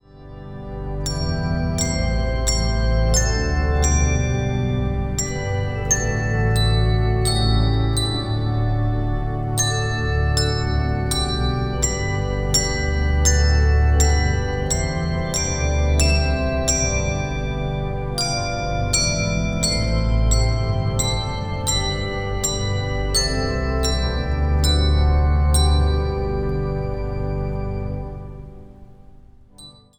orgel
piano
bariton
viool
handbells.
Zang | Mannenkoor